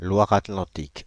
Loire-Atlantique (French pronunciation: [lwaʁ atlɑ̃tik]
Fr-Paris--Loire-Atlantique.ogg.mp3